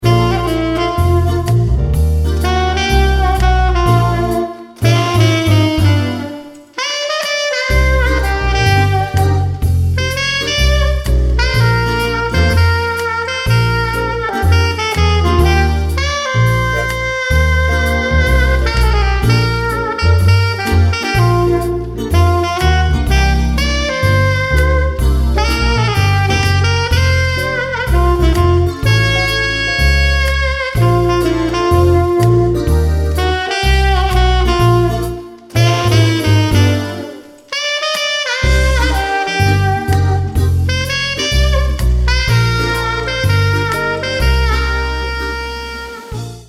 saxophonist
and the instrumentals have a relaxed,lite-jazz quality.